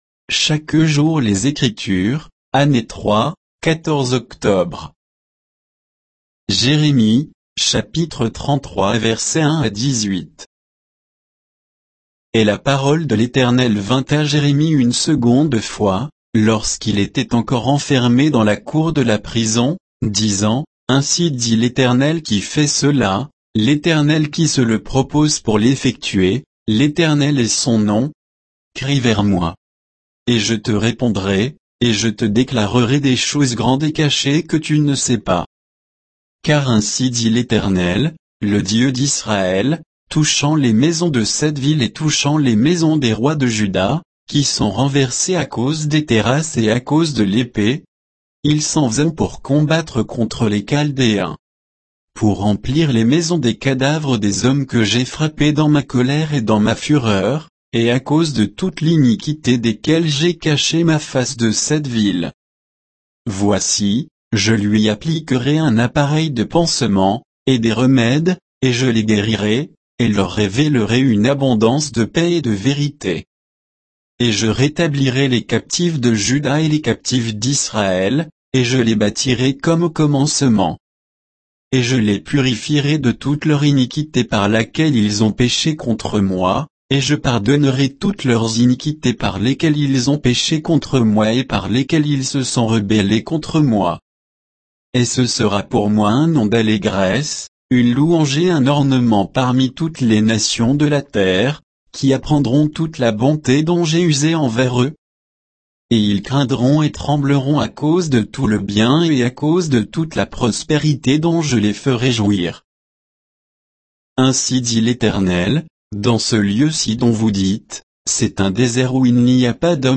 Méditation quoditienne de Chaque jour les Écritures sur Jérémie 33